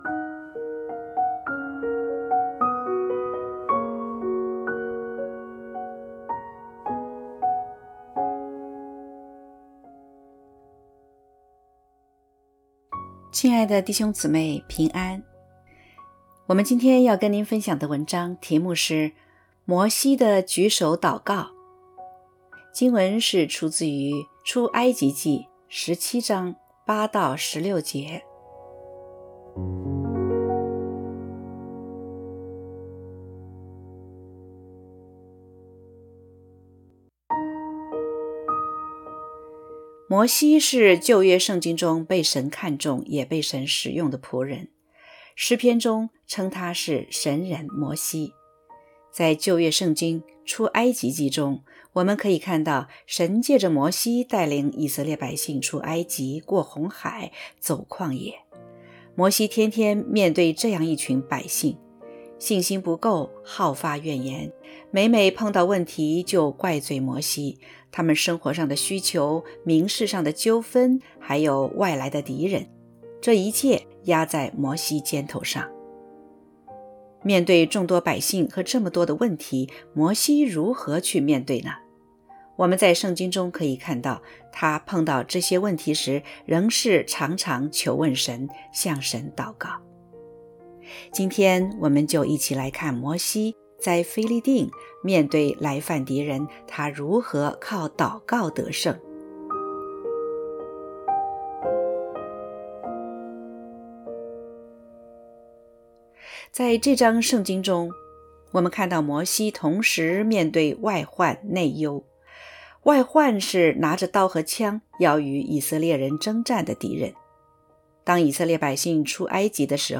（合成）M摩西的举手祷告.mp3